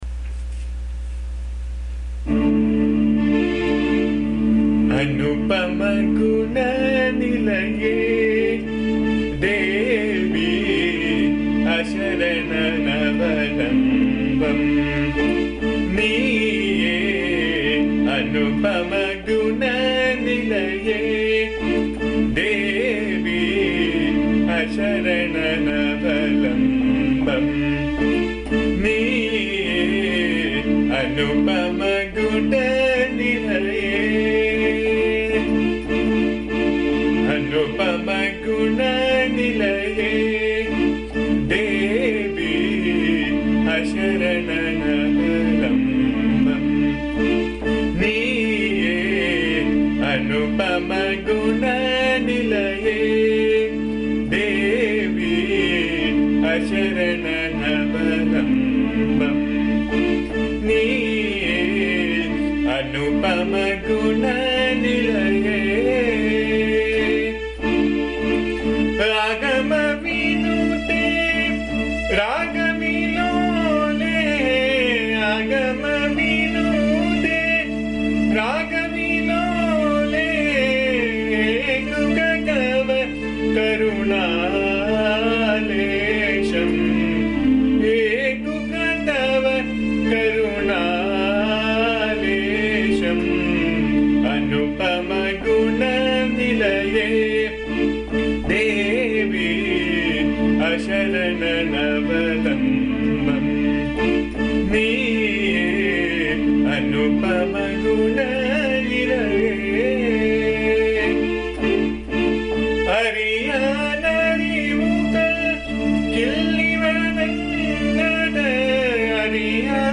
This is a simple song set partly in Darbaari Kaanada and Bhimpalas. The lyrics are simple and speak about a devotee's yearn for the Mother's compassion in order to realize Brahman.
Please bear the noise, disturbance and awful singing as am not a singer.
AMMA's bhajan song